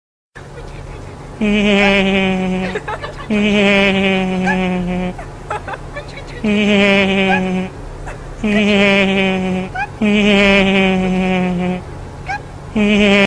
Sound Buttons: Sound Buttons View : Peter Griffen Laugh
Peter-Griffin-laugh.mp3